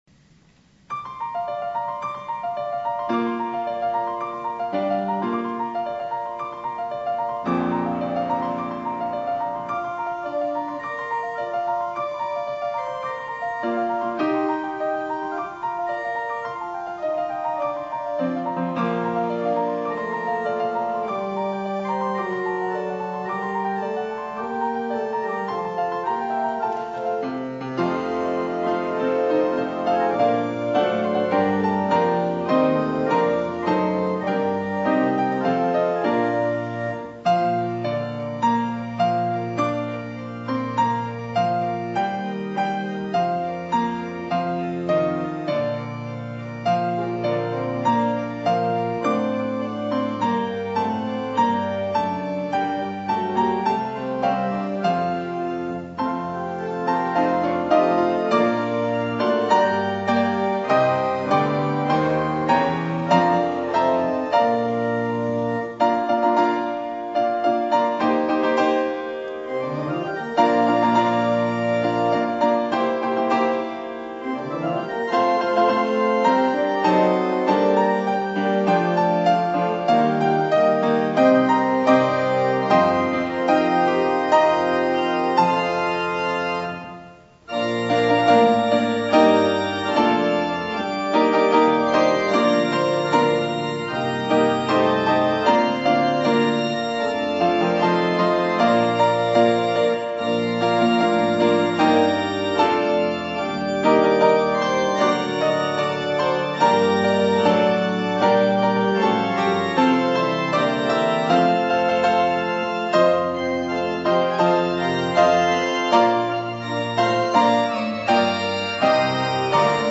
Christmas Concert 2018